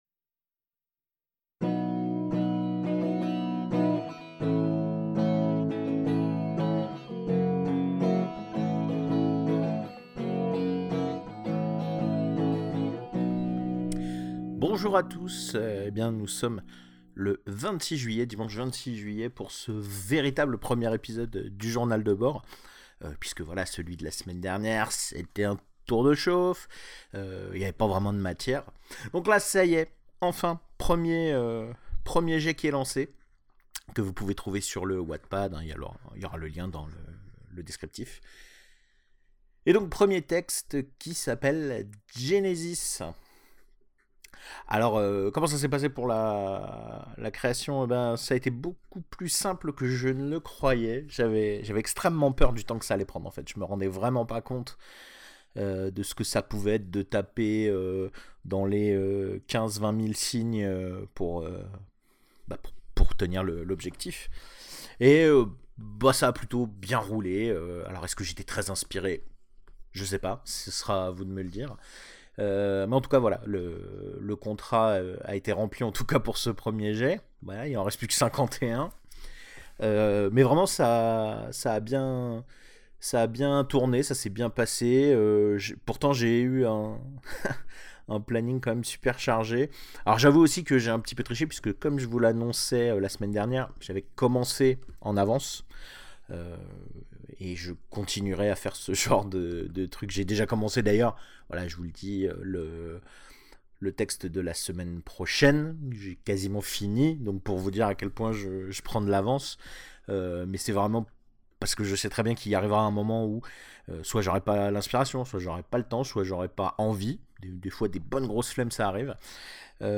(désolé, en réécoutant le mixage je me suis rendu compte qu’il y’a un p’tit couac à un moment mais comme je veux garder un coté « naturel » à ce podcast je ne voulais pas refaire …